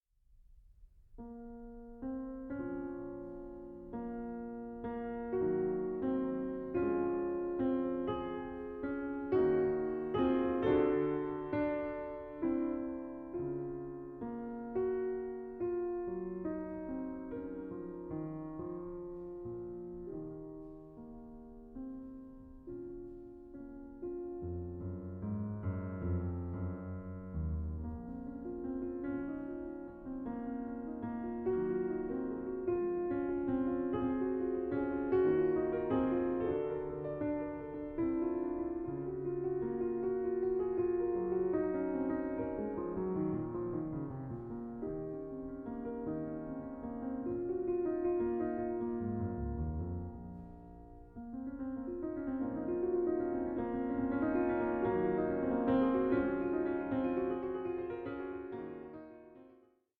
in D-Flat Major: Fugue 1:57